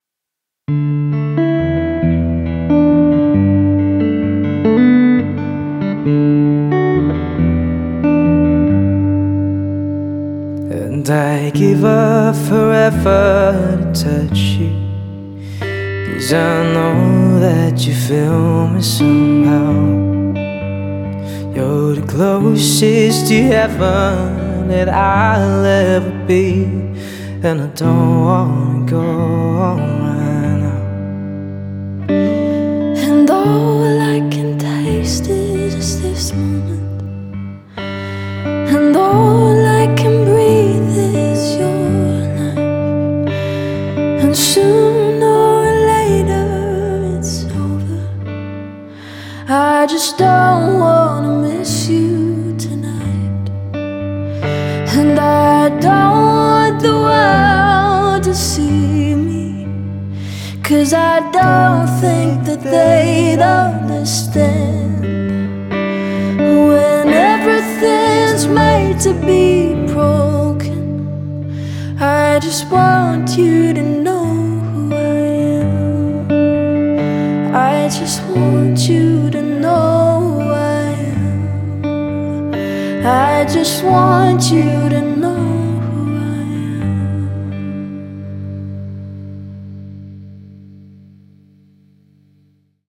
Dual Vocals | Guitar | Looping